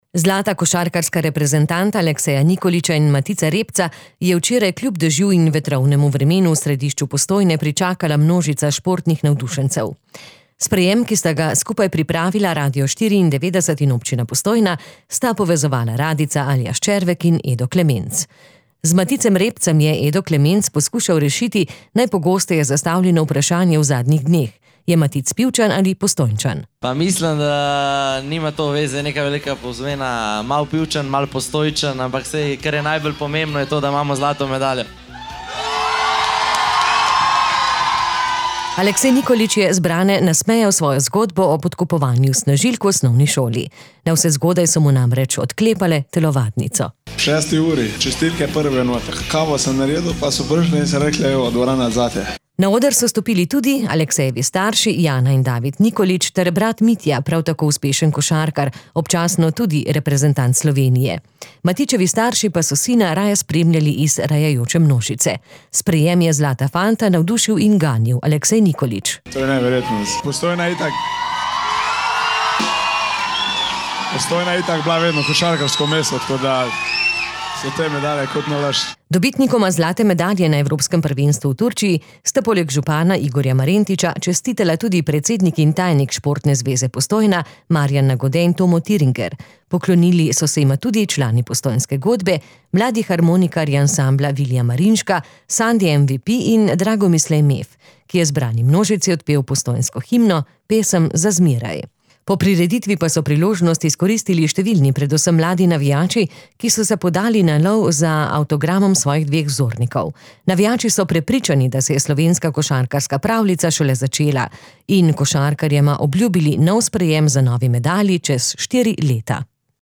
Košarkarska reprezentanta Alekseja Nikolića in Matica Rebca je včeraj popoldne kljub dežju in vetrovnemu vremenu v središču Postojne pričakala množica športnih navdušencev.
Matica Rebca smo vprašali, ali je Postojnčan ali Pivčan. Aleksej Nikolić pa je zbrane nasmejal z zgodbo o podkupovanju s kavo.